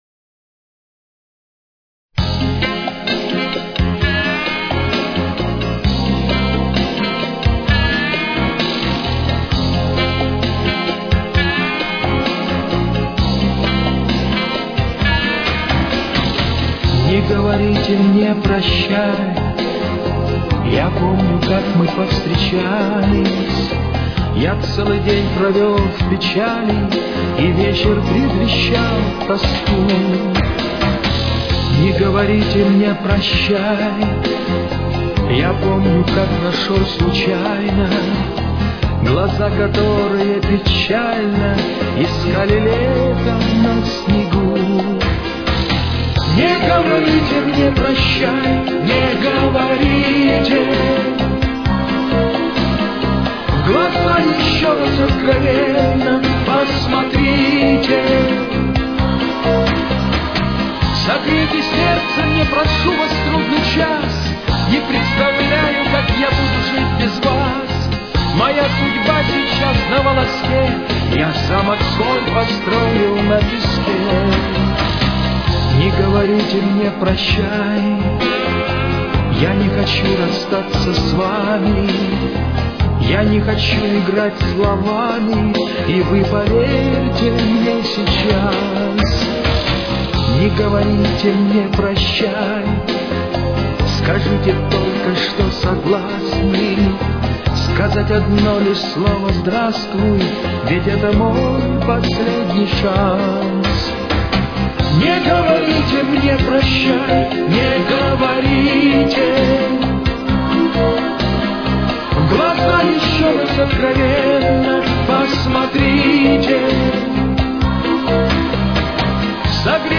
Темп: 131.